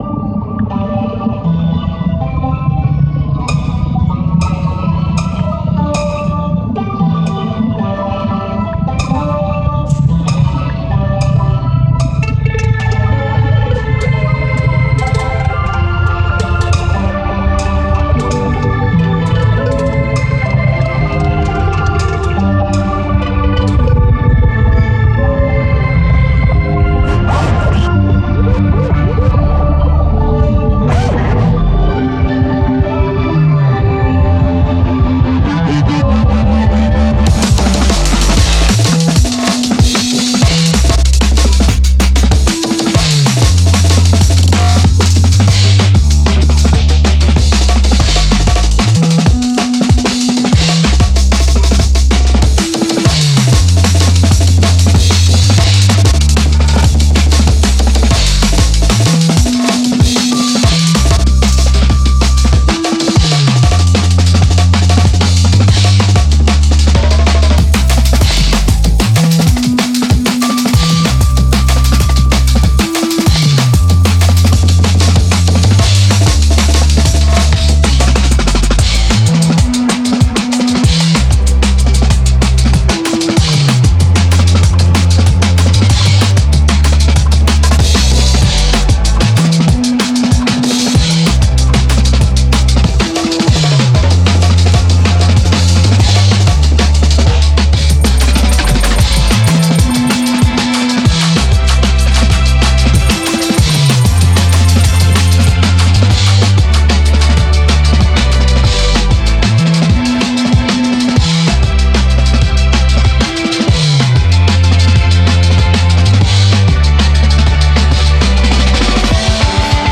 A soundtrack to a fictional film.
Genre Soundtrack